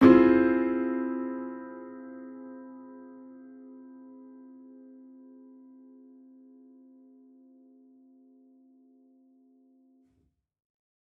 Index of /musicradar/gangster-sting-samples/Chord Hits/Piano
GS_PiChrd-Csus4min6.wav